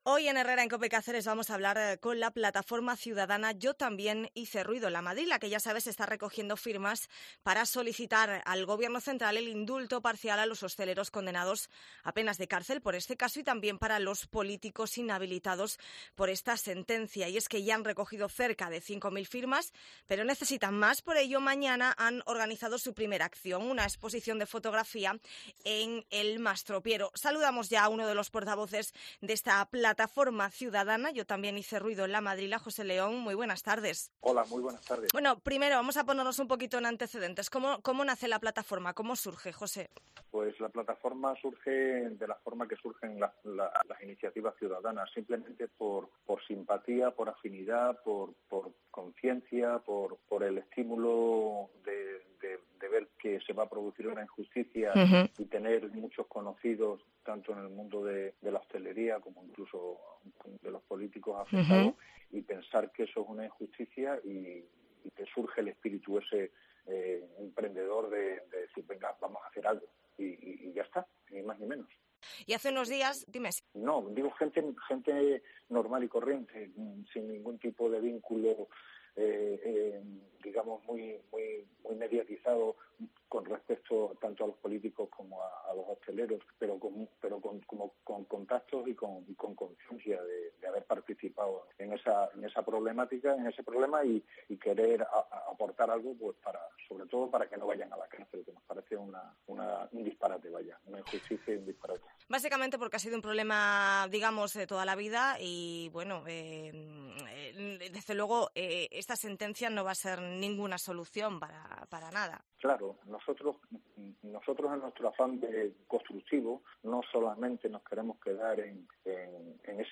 ENTREVISTA HERRERA EN COPE CÁCERES YO TAMBIÉN HICE RUIDO EN LA MADRILA